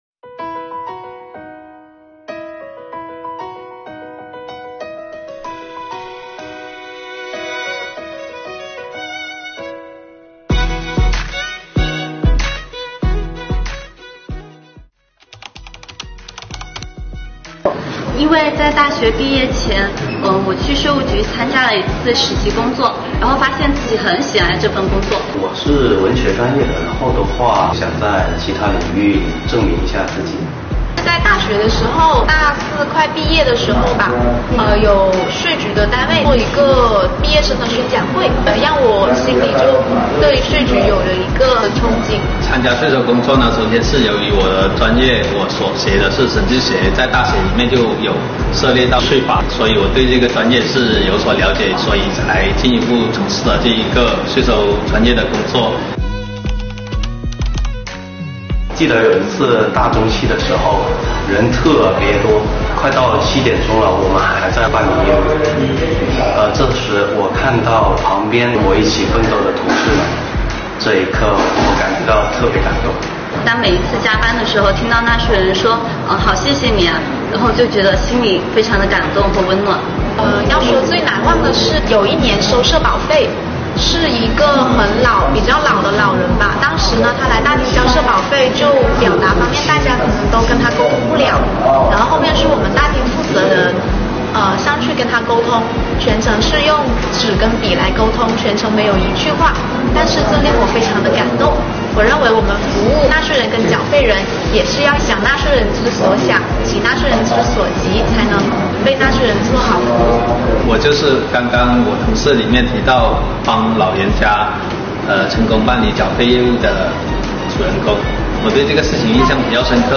我们采访了几名
北海市海城区税务局的青年税务干部